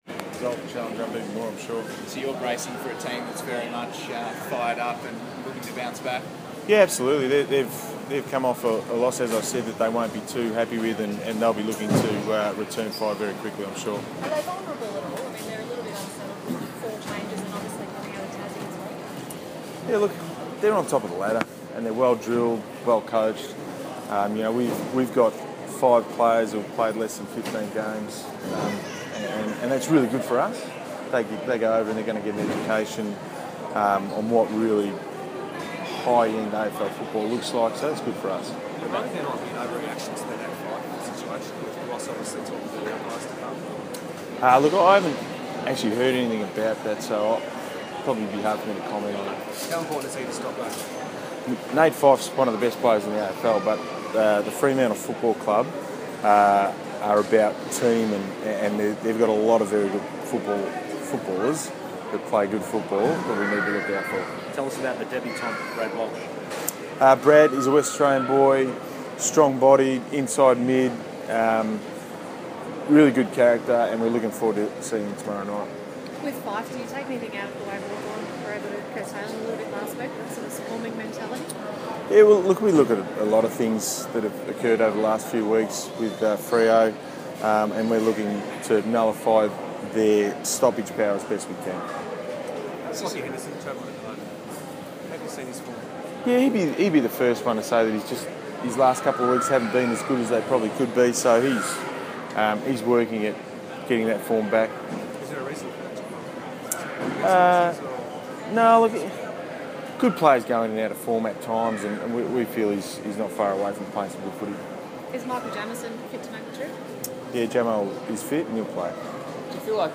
speaks to the media at Melbourne Airport before flying to Perth to take on Fremantle.